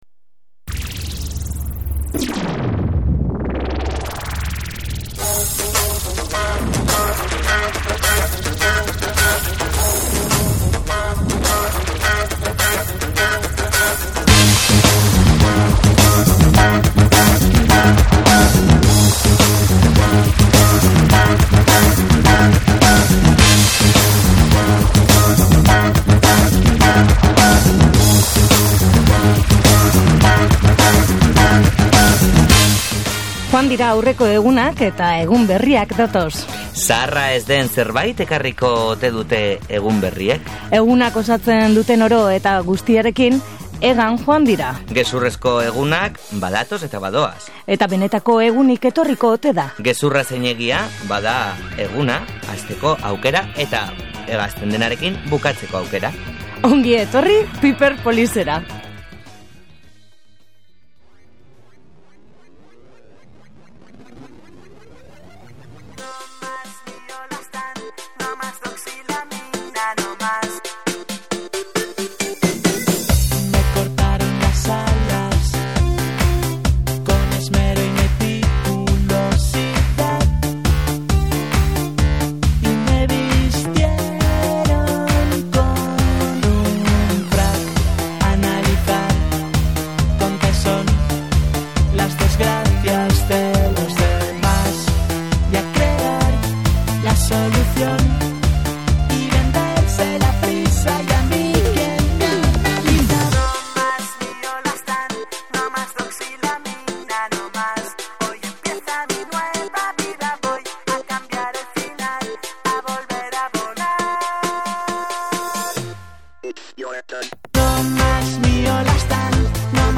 Programazio elektronikoak euskarri, jazza, funkya eta musika beltzean oinarritutako musika lantzen dute. Dantzarako gonbitea da bere proposamena groove, acid, tekno edo soul erritmoen bitartez.